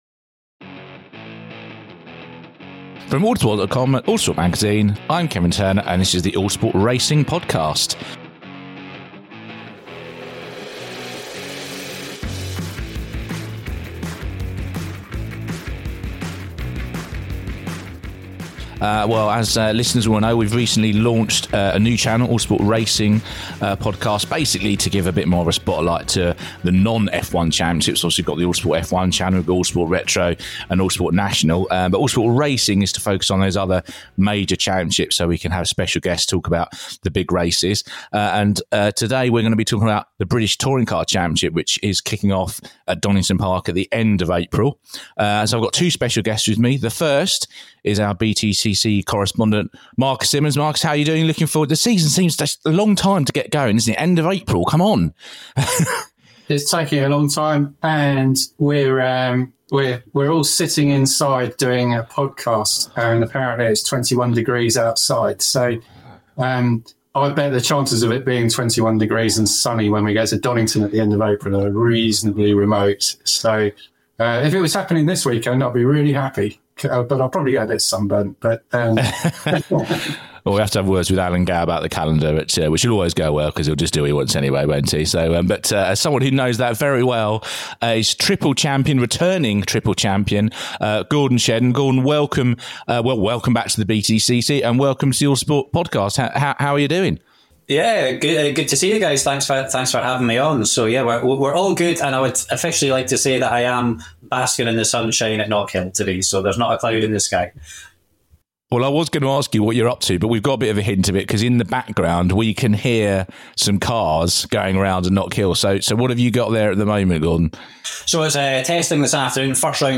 It's a wide-ranging interview covering nearly 40 minutes on why Gordon's chosen now to return to the BTCC with Toyota Gazoo Racing for 2025, the differences between the early feelings of Toyota machinery and the Honda's took to glory in the 2010's, what the internal feeling was like within Team Dynamics as the team collapsed at the start of 2023, his time in the World Touring Car Cup, and what he thinks would make for a good 2025 season with his BTCC return less than a month away.